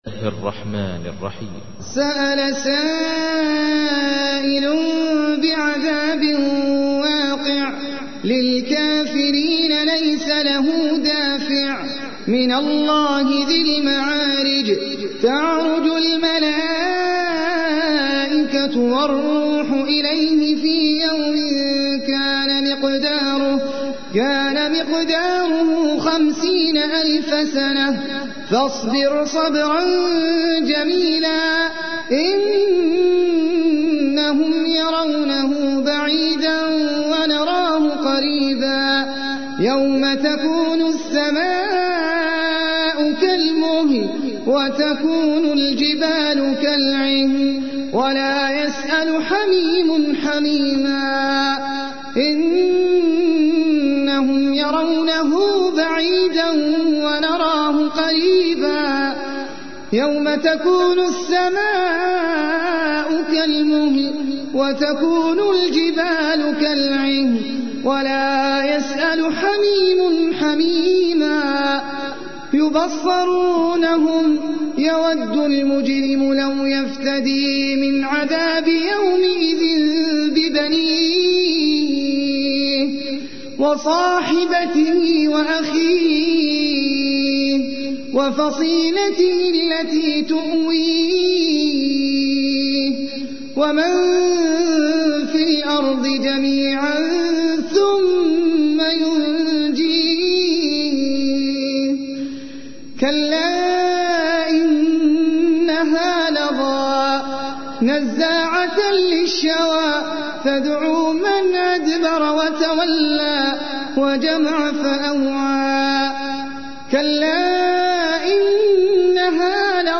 تحميل : 70. سورة المعارج / القارئ احمد العجمي / القرآن الكريم / موقع يا حسين